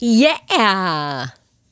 Yeah.wav